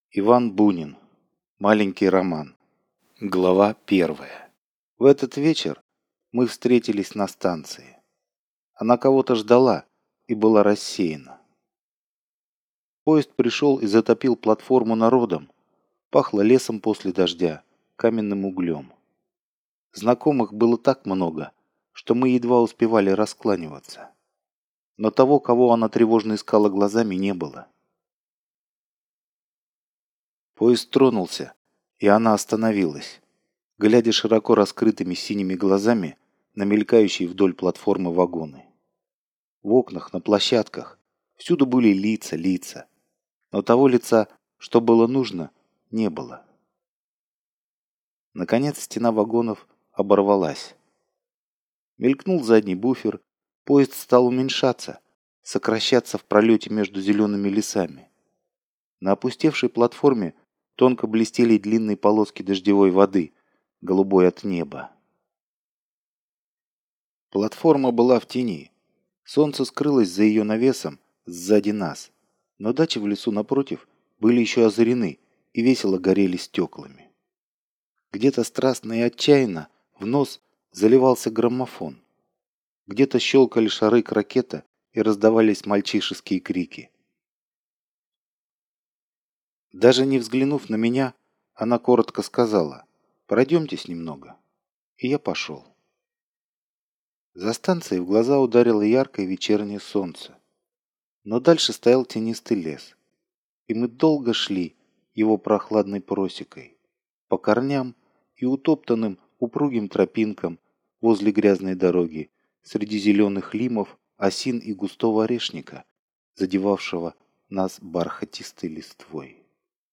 Аудиокнига Маленький роман | Библиотека аудиокниг
Aудиокнига Маленький роман Автор Иван Бунин Читает аудиокнигу Алгебра Слова.